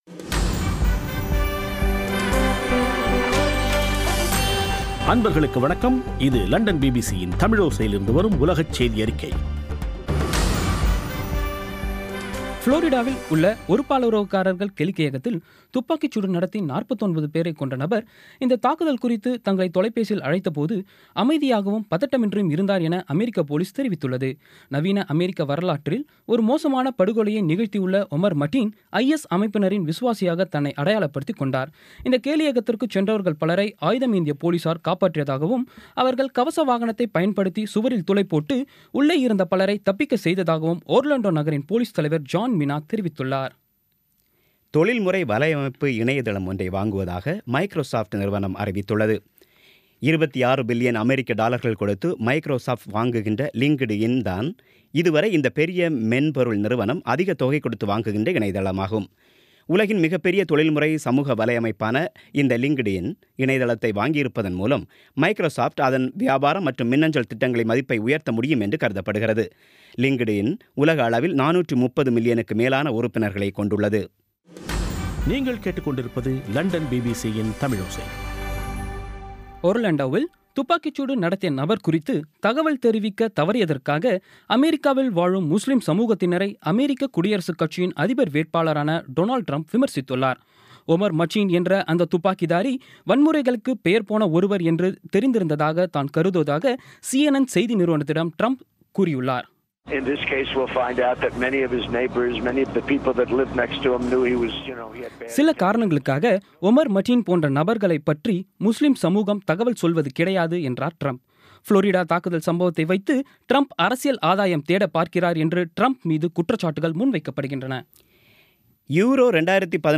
இன்றைய (ஜூன் 13ம் தேதி ) பிபிசி தமிழோசை செய்தியறிக்கை